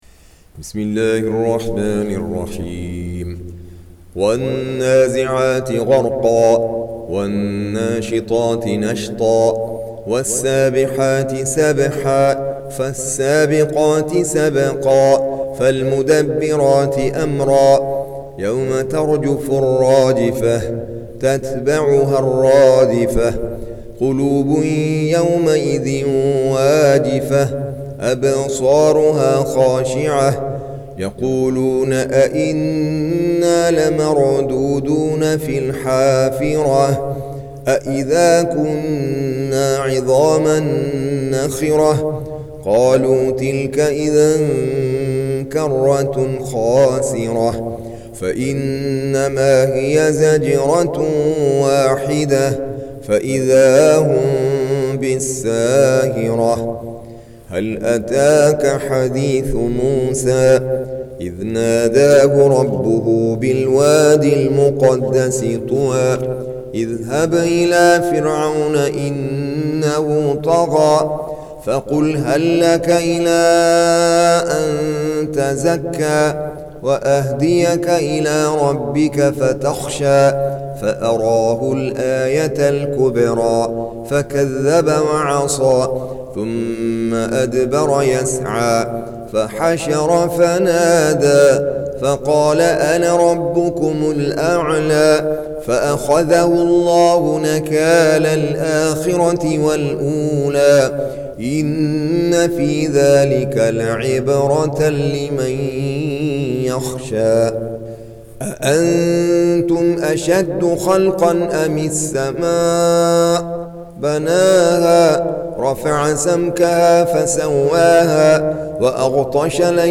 Surah Repeating تكرار السورة Download Surah حمّل السورة Reciting Murattalah Audio for 79. Surah An-Nazi'�t سورة النازعات N.B *Surah Includes Al-Basmalah Reciters Sequents تتابع التلاوات Reciters Repeats تكرار التلاوات